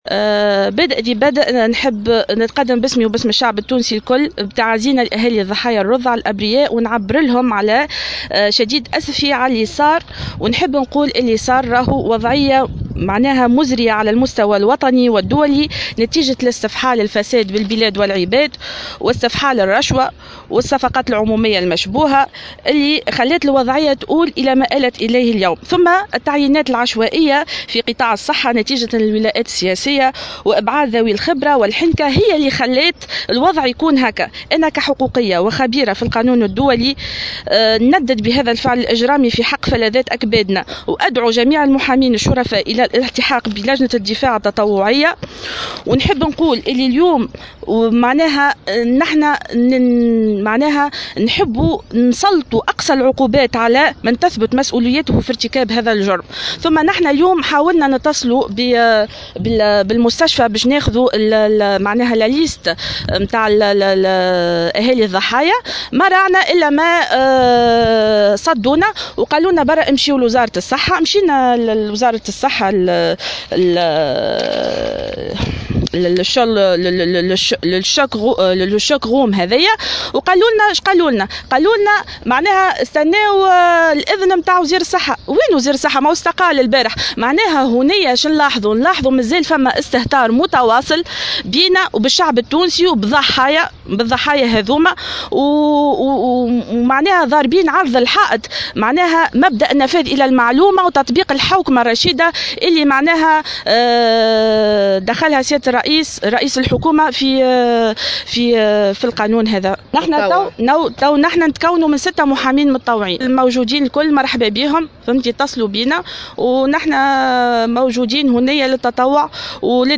في تصريح لمراسلة الجوهرة اف ام